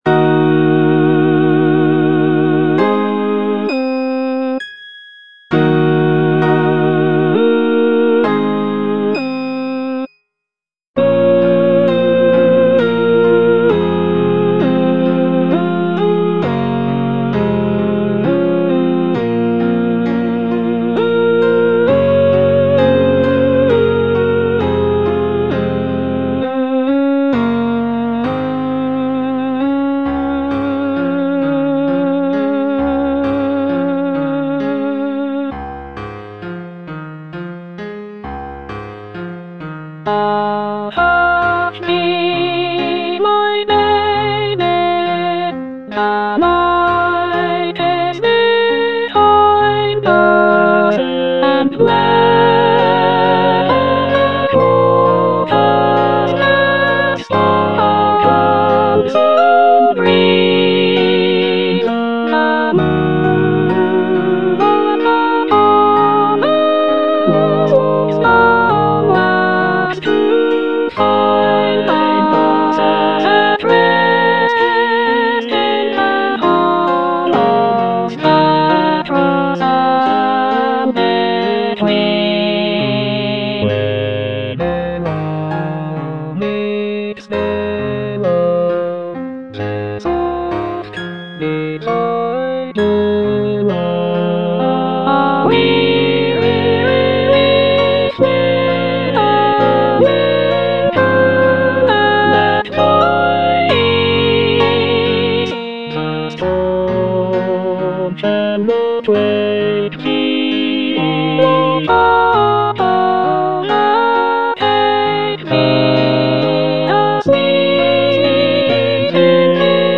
Soprano (Emphasised voice and other voices)
choral work